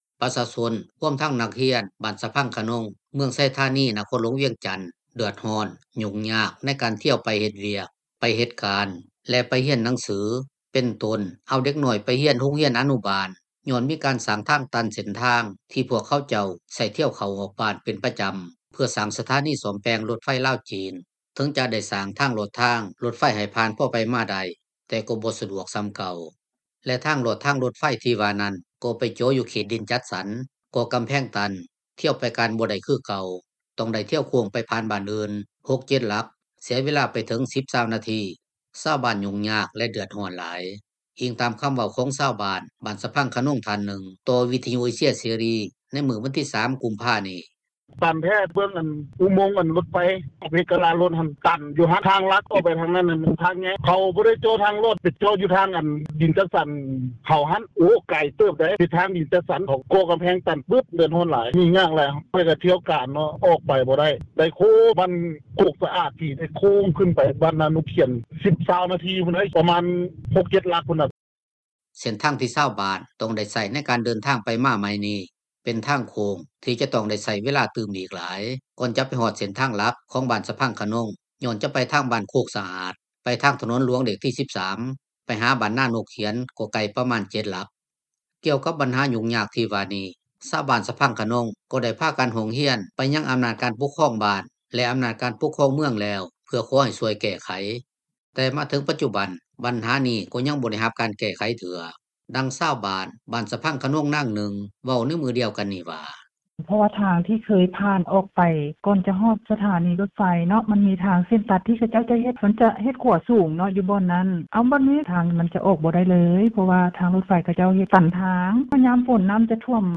ກ່ຽວກັບບັນຫາຫຍຸ້ງຍາກທີ່ວ່ານີ້ ຊາວບ້ານສະພັງຄະນົງ ກໍໄດ້ພາກັນຮ້ອງຮຽນ ໄປຍັງອໍານາດການປົກຄອງບ້ານ ແລະອໍານາດການປົກຄອງເມືອງແລ້ວ ເພື່ອຂໍໃຫ້ຊ່ວຍແກ້ໄຂ ແຕ່ມາເຖິງປັດຈຸບັນ ບັນຫານີ້ ກໍຍັງບໍ່ໄດ້ຮັບການແກ້ໄຂເທື່ອ ດັ່ງຊາວບ້ານ ບ້ານສະພັງຄະນົງນາງໜຶ່ງເວົ້າໃນມື້ດຽວກັນນັ້ນວ່າ: